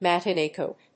アクセントmatinée còat [jàcket]